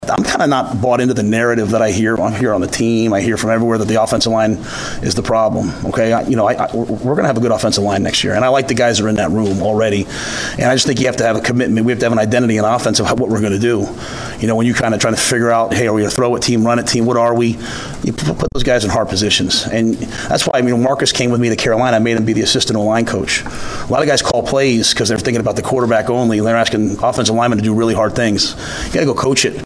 Head Coach Matt Rhule was asked at his press conference if there was an importance to find more talent on the offensive line…